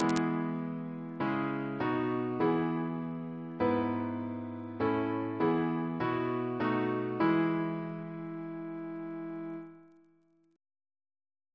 CCP: Chant sampler
Single chant in E Composer: Richard Farrant (d.1580), Organist of St. George's Windsor Reference psalters: ACB: 351; ACP: 55 288; H1940: 619 672; H1982: S206; OCB: 33; PP/SNCB: 15